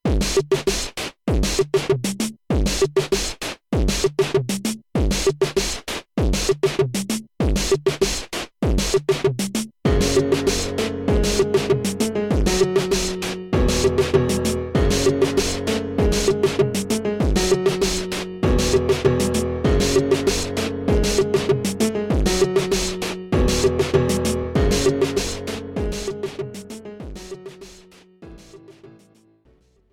Without Backing Vocals. Professional Karaoke Backing Track.
Hip Hop